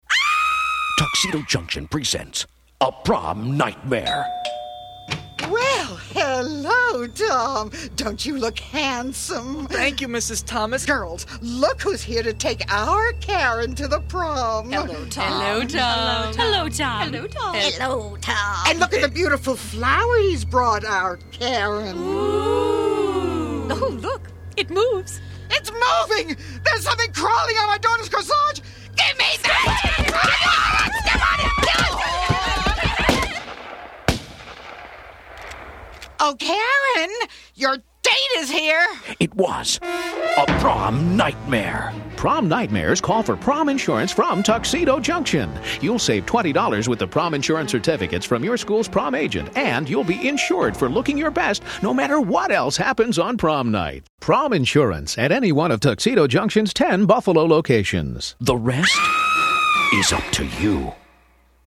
Through a series of four 60-second radio spots, we created a memorable campaign geared towards teens. Each spot featured a humorous "prom nightmare," encouraging kids to purchase prom insurance from Tuxedo Junction.